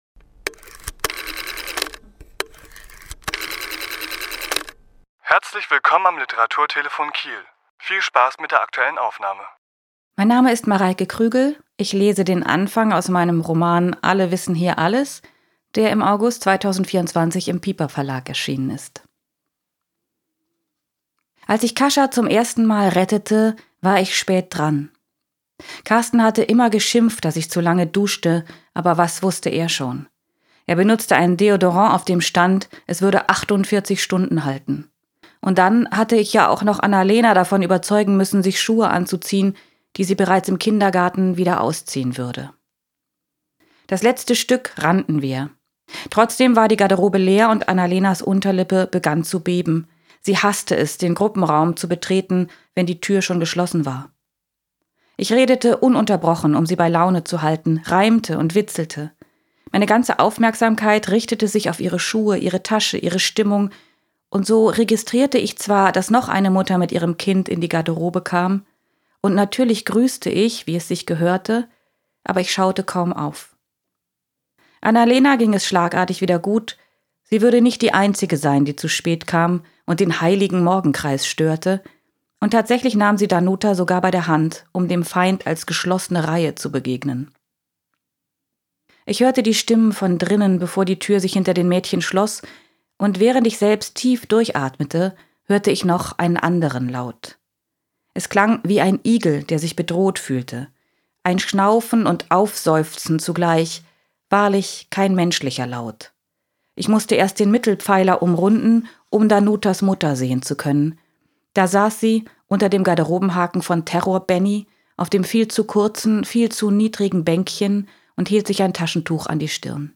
Autor*innen lesen aus ihren Werken
Die Aufnahme entstand anlässlich einer Lesung im Literaturhaus S.-H. am 12.9.2024.